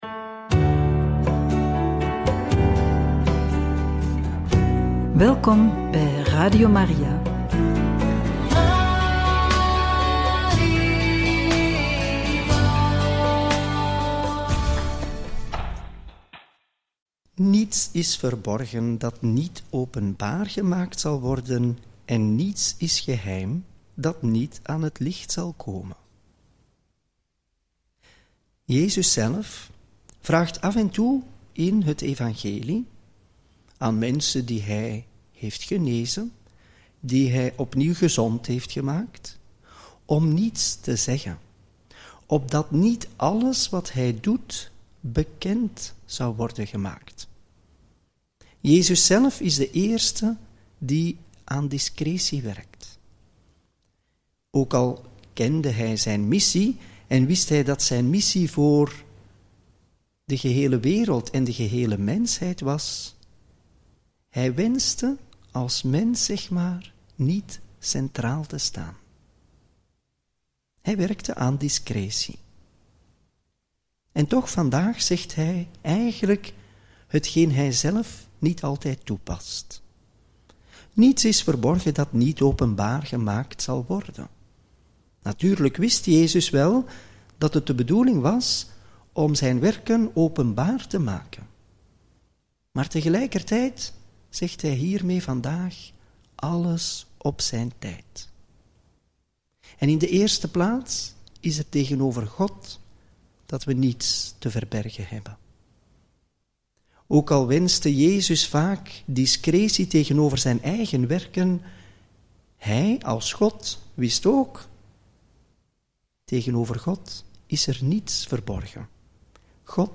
Homilie bij het Evangelie van donderdag 30 januari 2025 – Marcus 4, 21-25